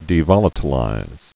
(dē-vŏlə-tl-īz)